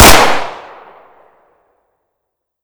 Tommy Gun Drop / gamedata / sounds / weapons / thompson / thompson_2.ogg